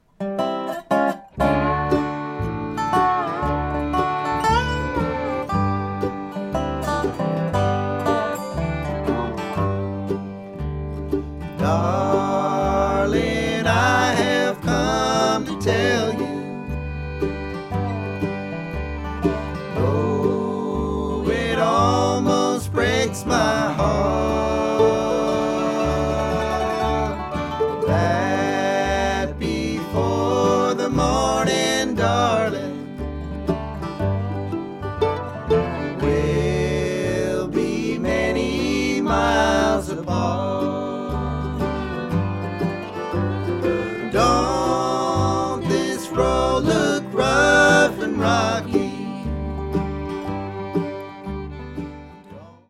Band version (key of G)